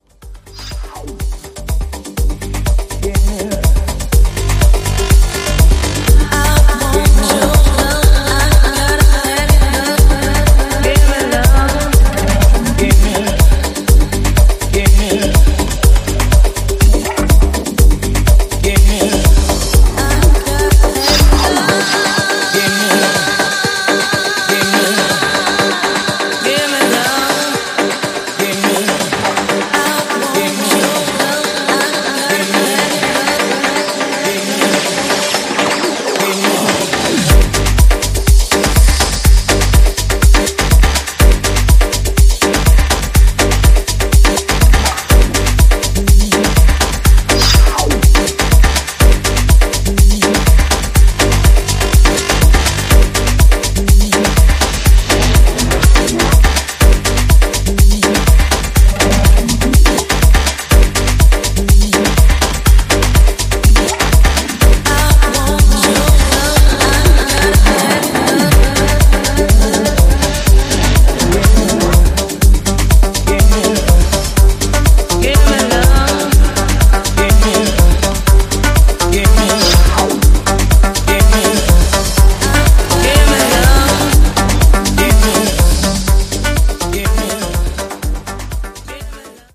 sophisticated, hypnotic drive